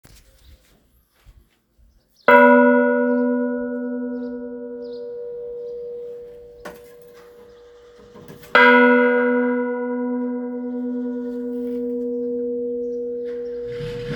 cloche - Inventaire Général du Patrimoine Culturel